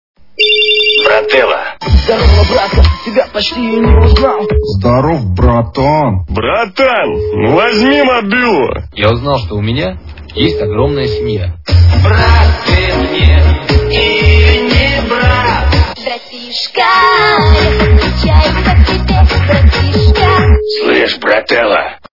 » Звуки » Смешные » Звонок Братану - Здорово, братан!
При прослушивании Звонок Братану - Здорово, братан! качество понижено и присутствуют гудки.